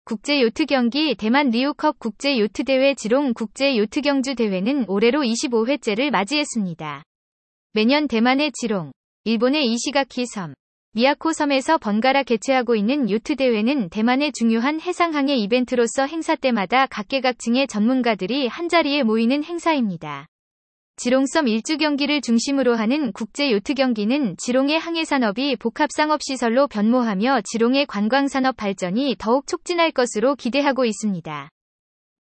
이 경로의 오디오 가이드 1분 무료 체험